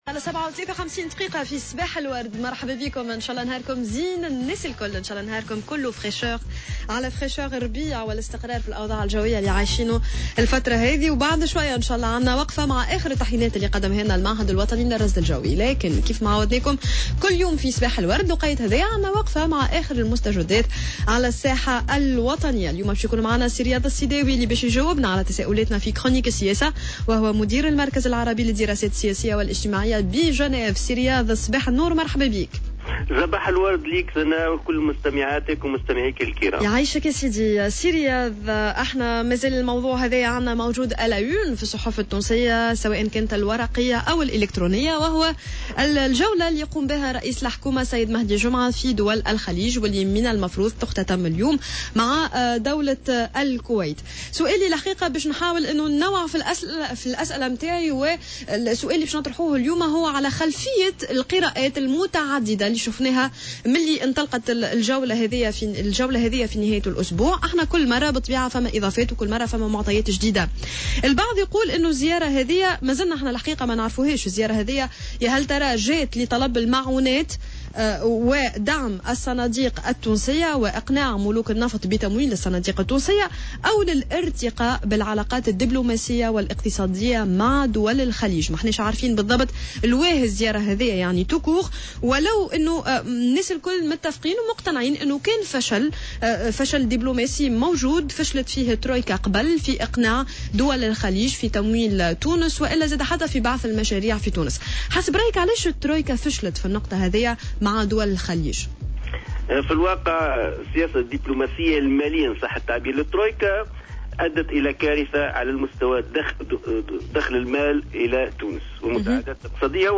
مداخلة له اليوم،الاربعاء في برنامج "صباح الورد" على "جوهرة أف أم"